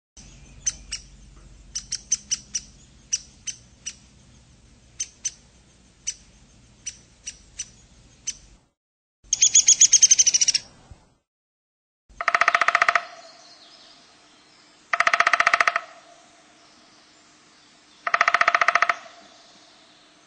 Downy Woodpecker
A Downy Woodpecker's "whinny" call descends in pitch toward the end, unlike the Hairy Woodpecker's call.
Bird Sound
The Downy Woodpecker's whinnying call, made by both sexes, is a typical sound of deciduous forests during the breeding season. It's a somewhat excited string of hoarse, high-pitched notes that descend in pitch toward the end; the call lasts about 2 seconds. Excited birds also give a very sharp pik note, occasionally repeated several times.
DownyWoodpecker.mp3